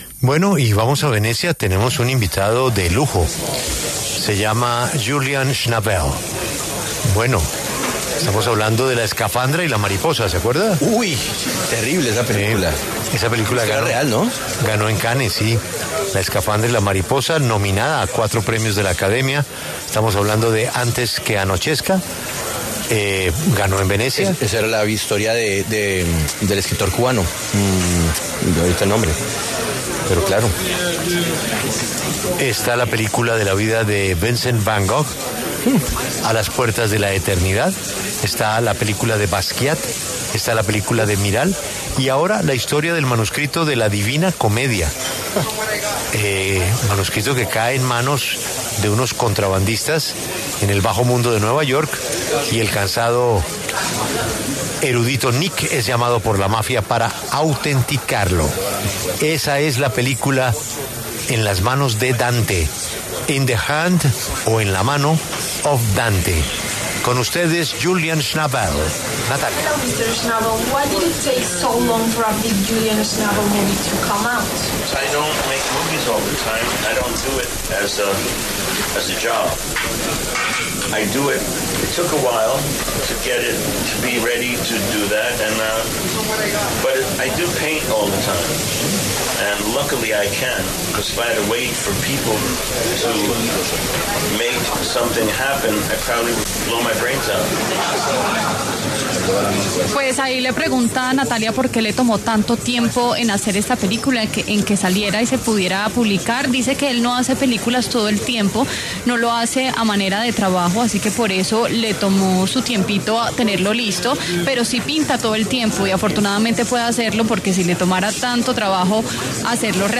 Julian Schnabel, director de la película “In the Hand of Dante”, conversó con La W desde la 82° edición del Festival Internacional de Cine de Venecia.